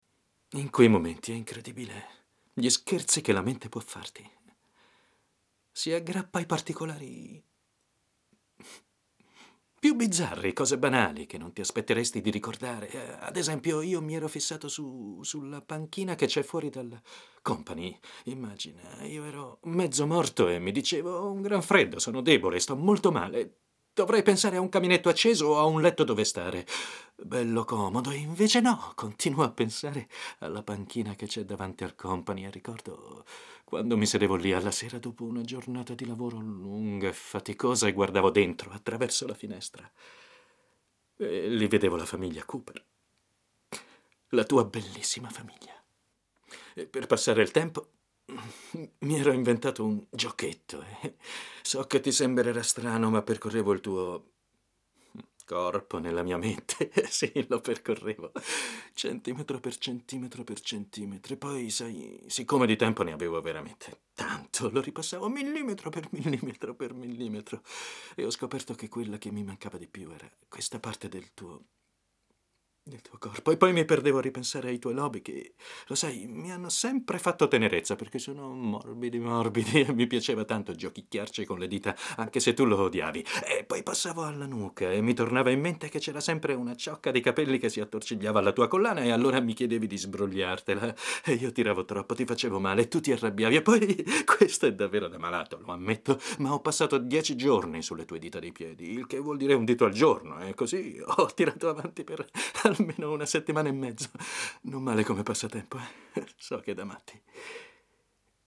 nella soap opera "Sentieri", in cui doppia Ricky Paull Goldin.